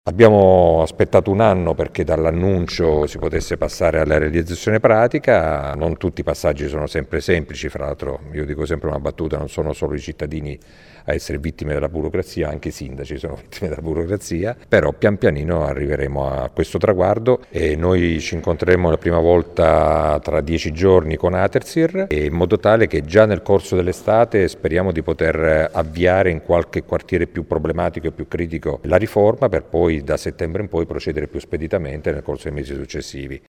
Bisognerà attendere l’approvazione di Artesir per estendere lo stessa modalità a tutta la città, dice il sindaco Massimo Mezzetti: